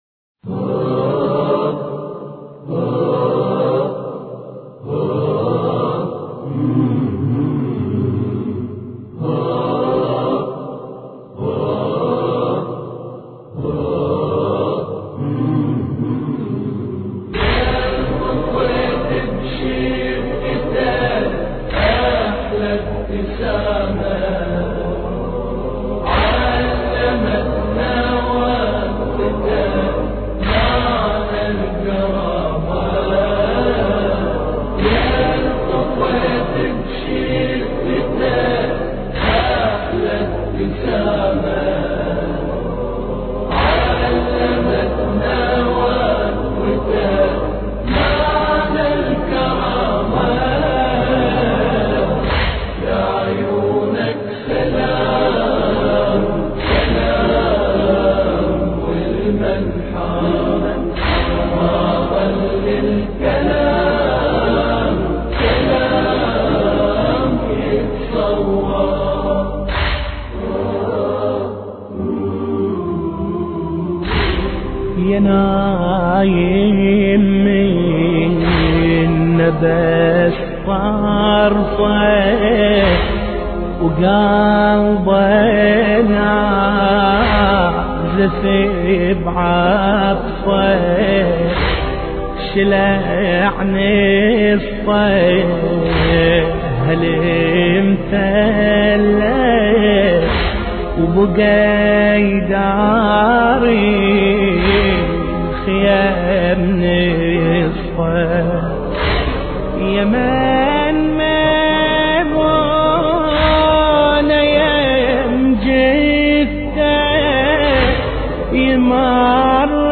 مراثي أهل البيت (ع)